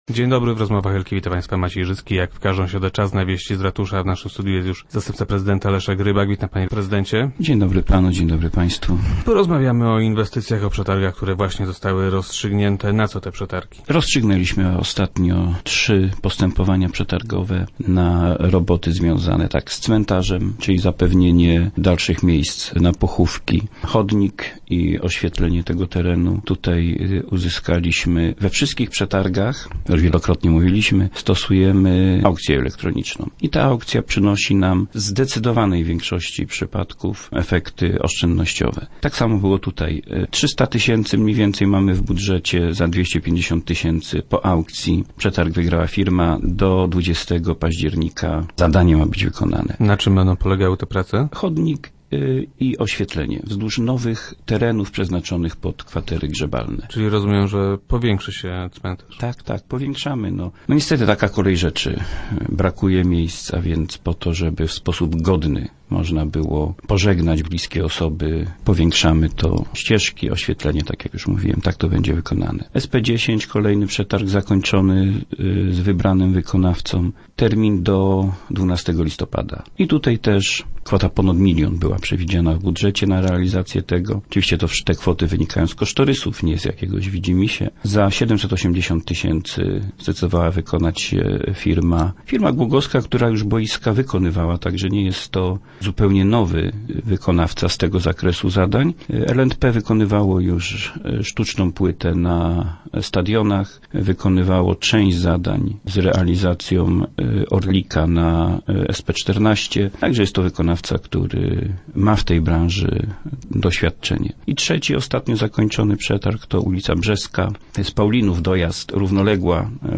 Rozstrzygnięty został już przetarg na jego powiększenie. - Dzięki elektronicznym aukcjom zapłacimy za to oraz za inne zadania mniej niż planowaliśmy - informuje Leszek Rybak, zastępca prezydenta Głogowa.